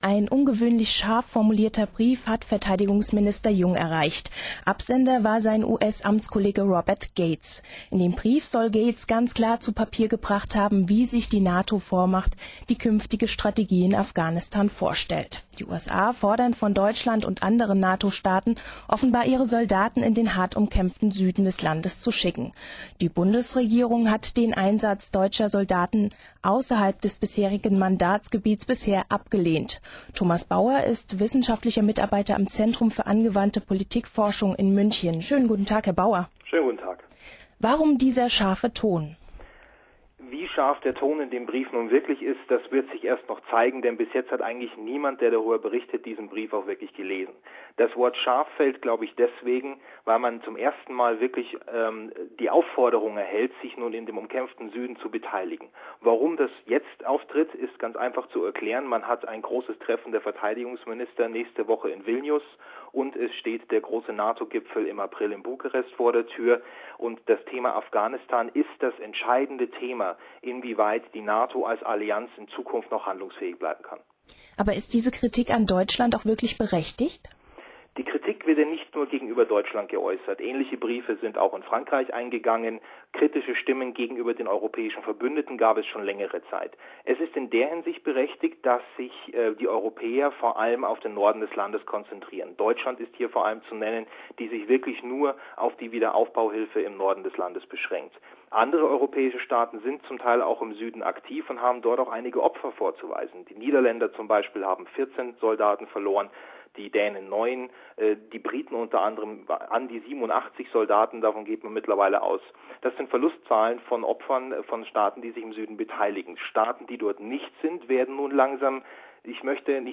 US-Verteidigungsminister Gates fordert die NATO-Partner zu mehr Engagement auf - Interview